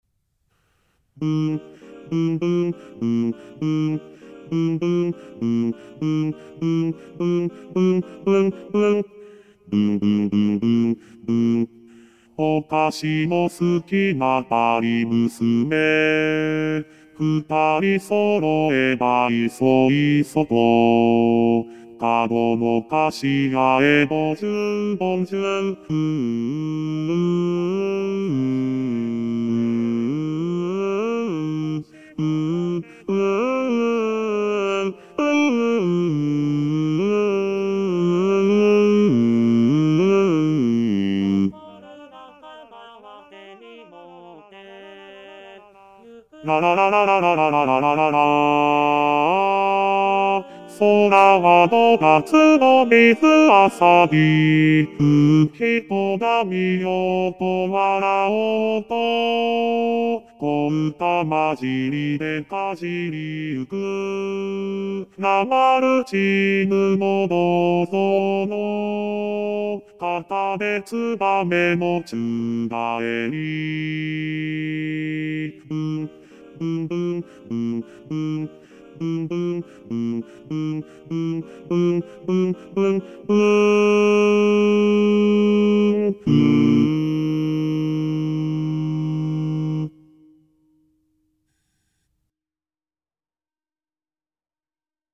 ★第１２回定期演奏会　演奏曲　パート別音取り用 　機械音声(ピアノ伴奏希望はｽｺｱｰﾌﾟﾚｱｰsdxで練習して下さい)